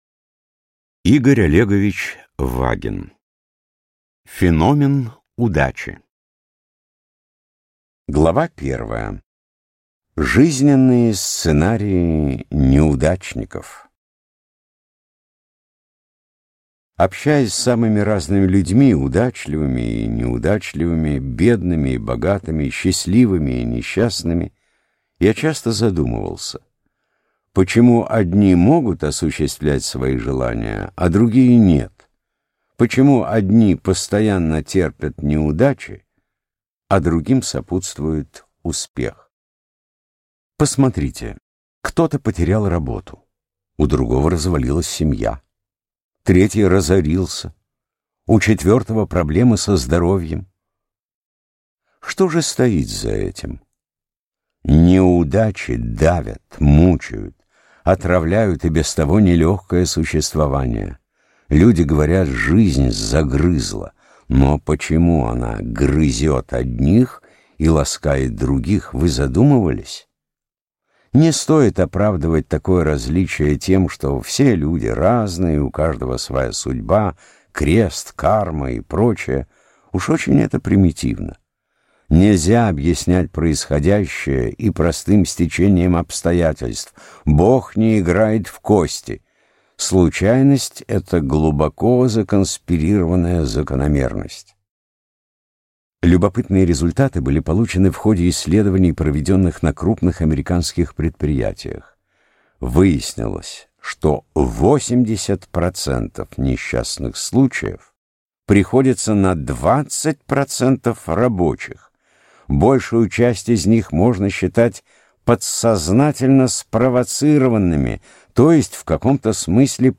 Аудиокнига Феномен удачи | Библиотека аудиокниг